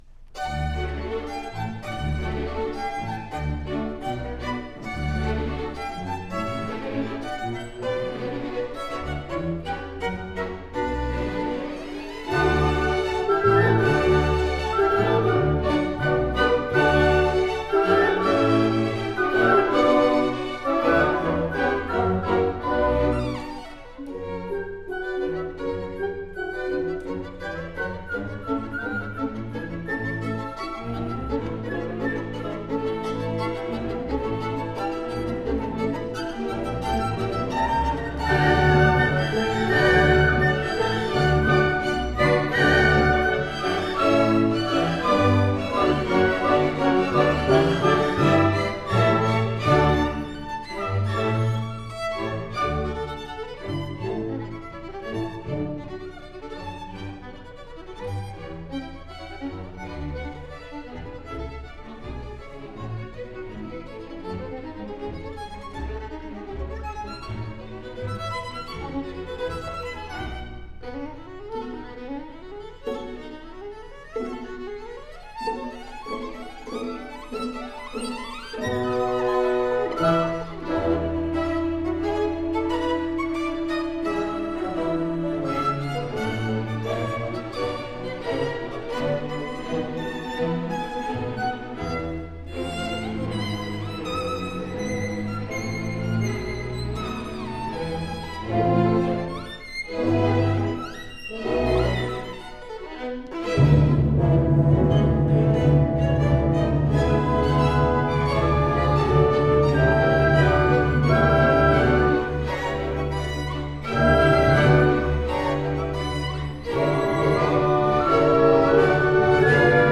2-03+Violin+Concerto+in+D+major,+Op..m4a